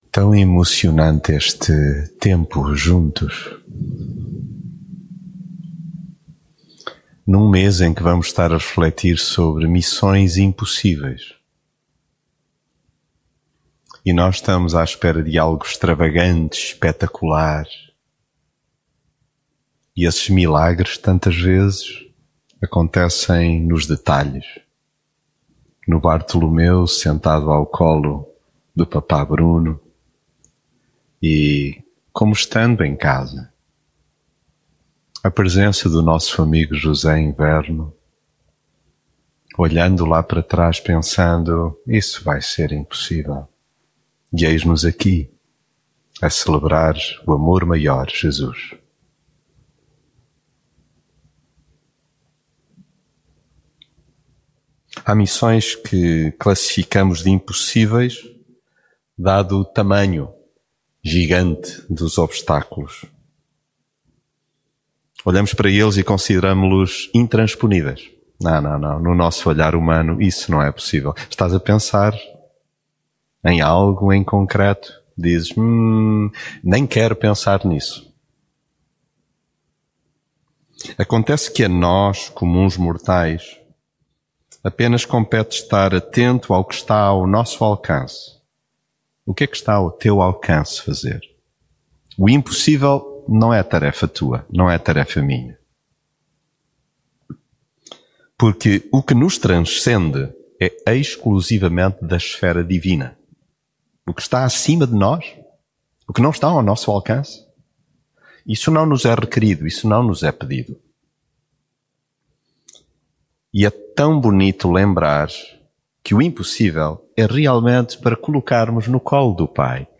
mensagem bíblica Existem cenários de reconstrução que colocamos na prateleira das fantasias irrealizáveis.